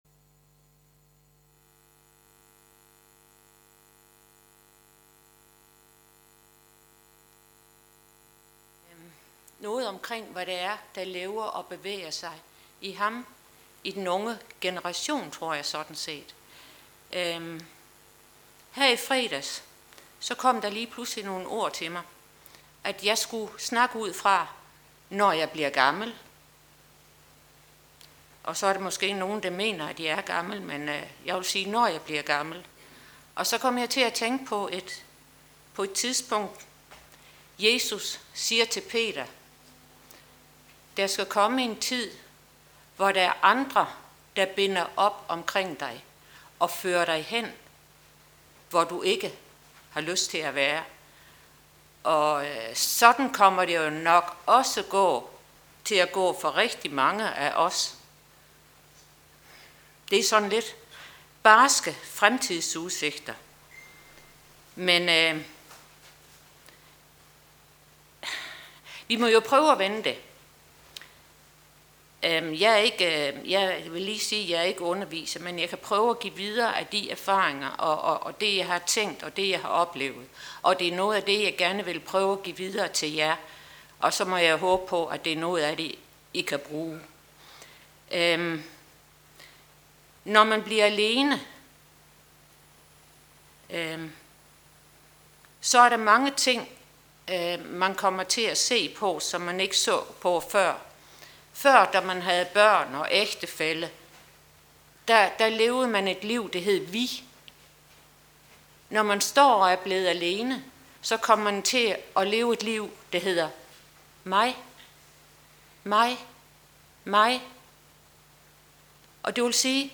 Advents Gudstjeneste - Tønder Frikirke
Her er der julehygge og sang af julesalmer.